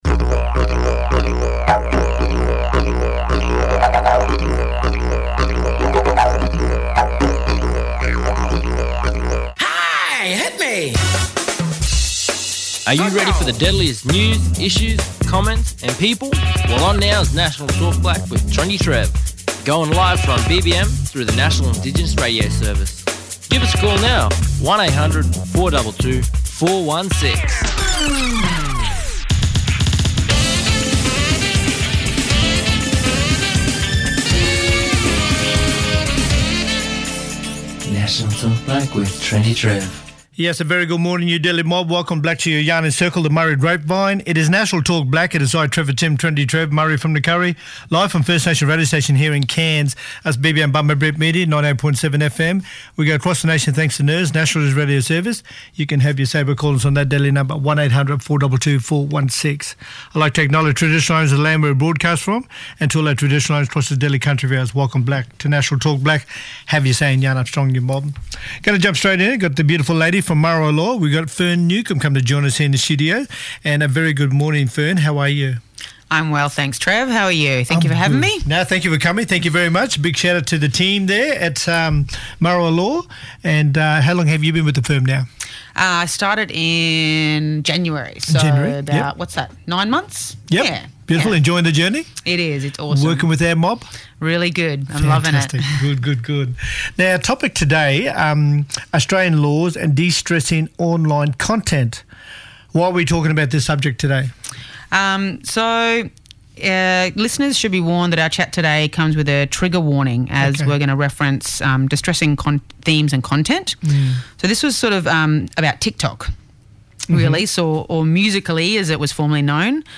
in the studio to discuss Tiktok: Australian laws and distressing online content.